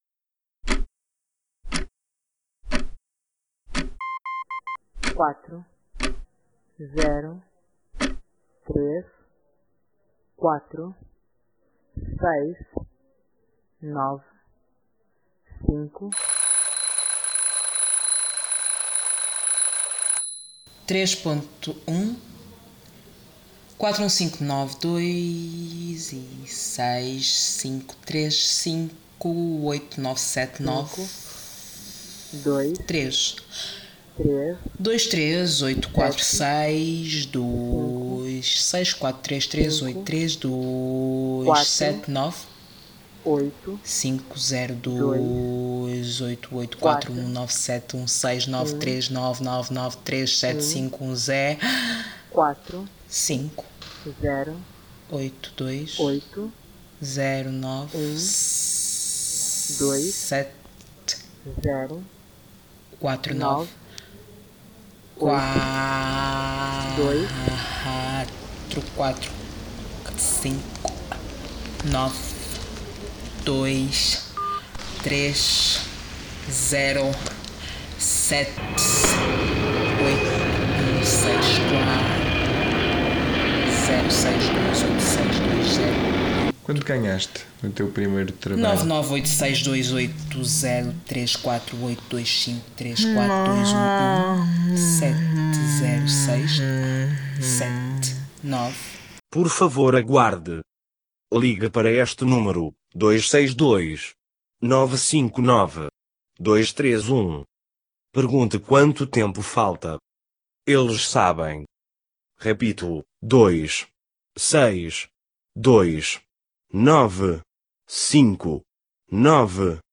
Soa a conversa entre máquinas (software text-to-speech):